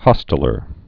(hŏstə-lər)